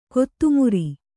♪ kottumari